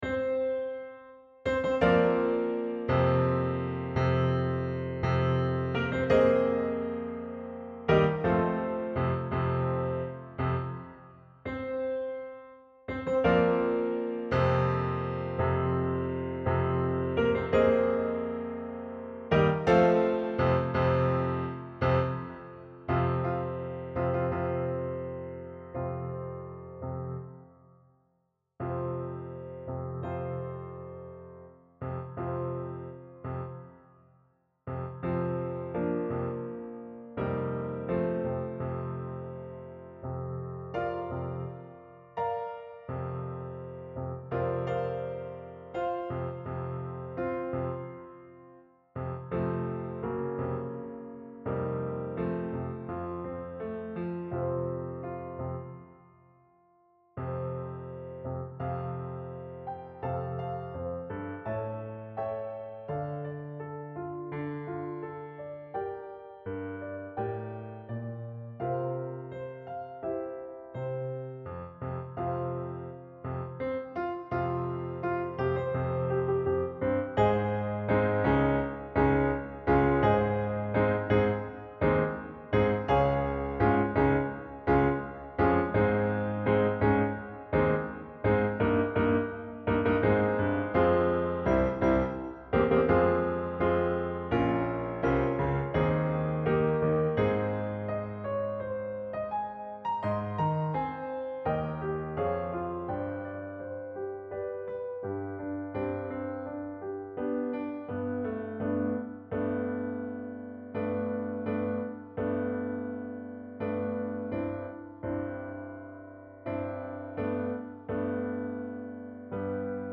BattleHymnSoloPianoTrack
BattleHymnSoloPianoTrack.mp3